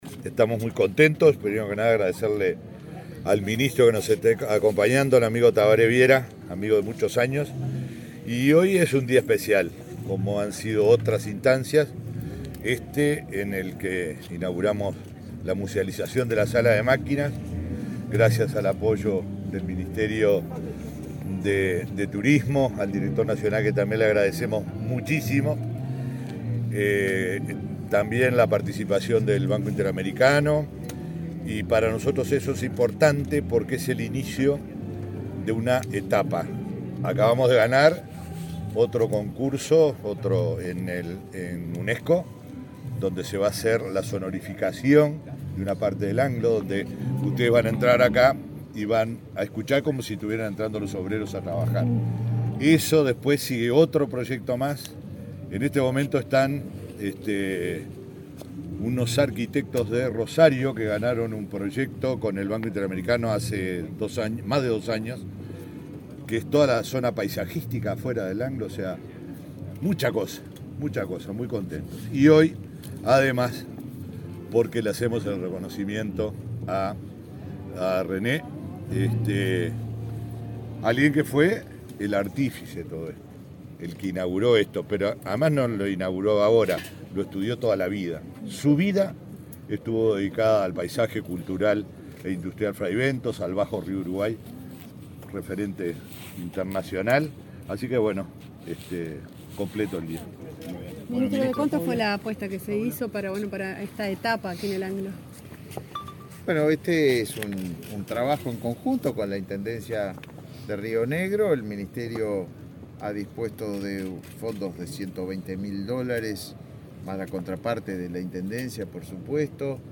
Declaraciones a la prensa del intendente de Río Negro y el ministro de Turismo
Declaraciones a la prensa del intendente de Río Negro y el ministro de Turismo 05/07/2022 Compartir Facebook X Copiar enlace WhatsApp LinkedIn El intendente de Río Negro, Omar Lafluf, y el ministro de Turismo, Tabaré Viera, dialogaron con la prensa antes de inaugurar la musealización de la sala de máquinas del ex frigorífico Anglo.